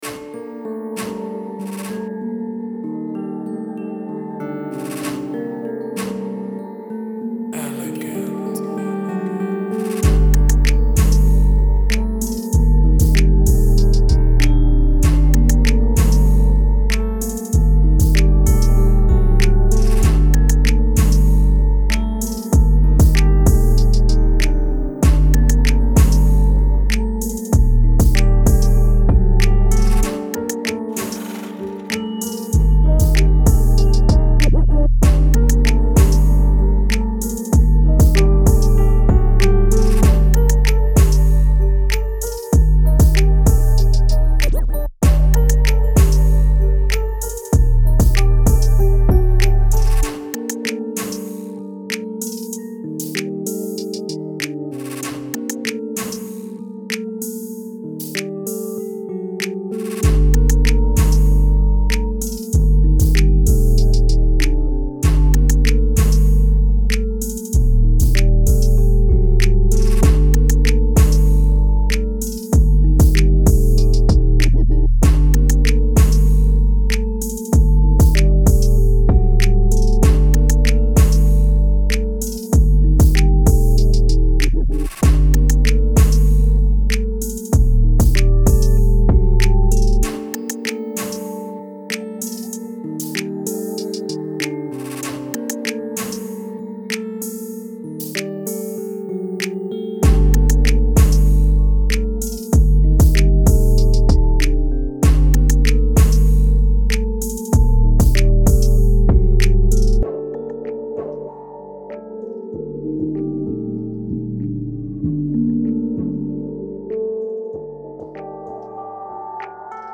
Hip-Hop , West Coast